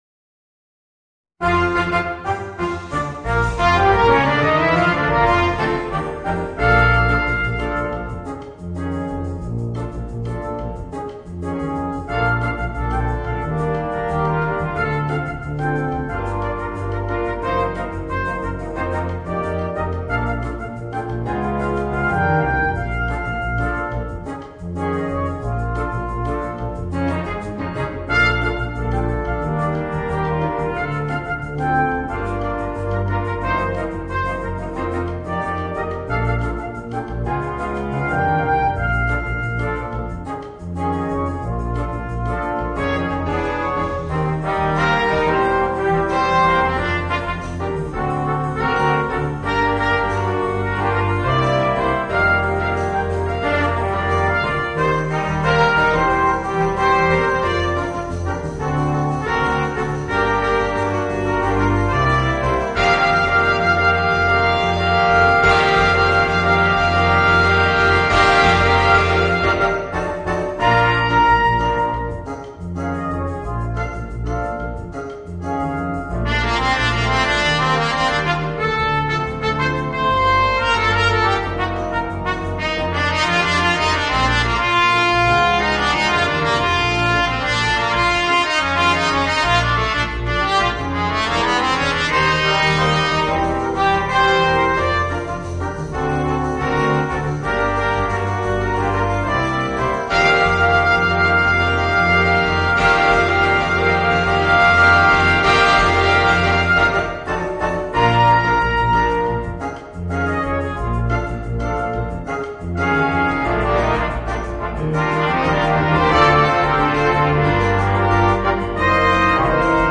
Voicing: 2 Trumpets, 2 Trombones and Tuba